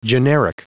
Prononciation du mot generic en anglais (fichier audio)
Prononciation du mot : generic